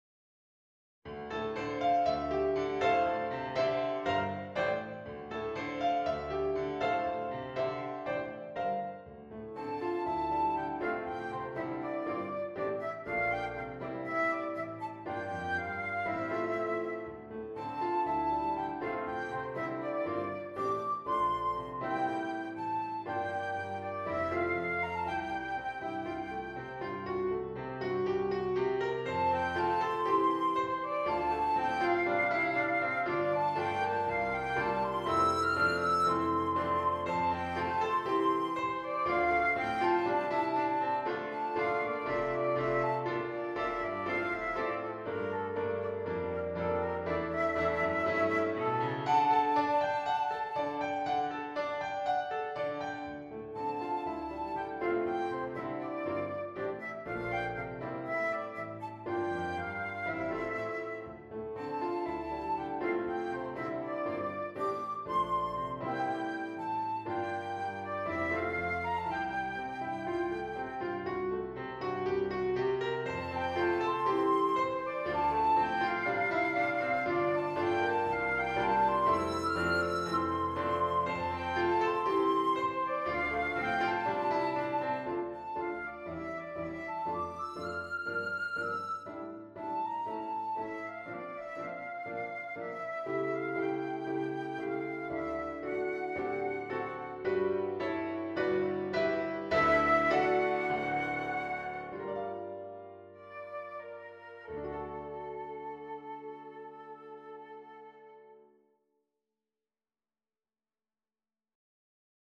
Flute version
• Piano score
• Reference mp3 Flute version